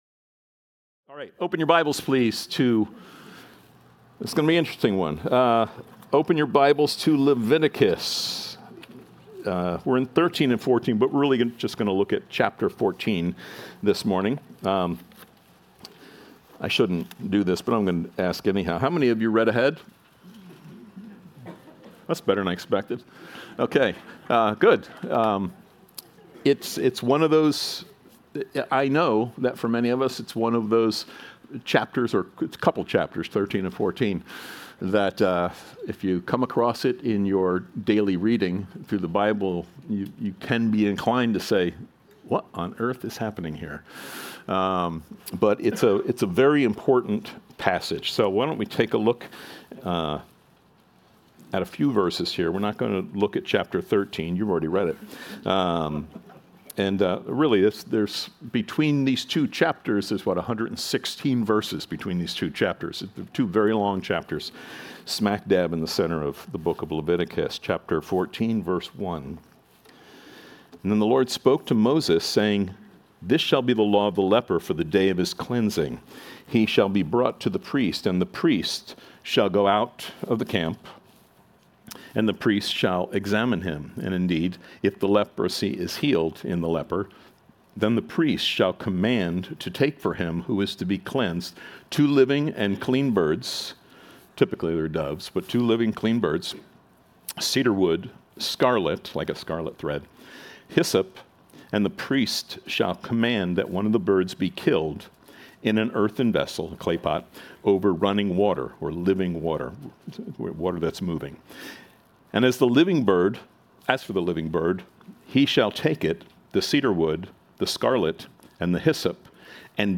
Sermons | Calvary Chapel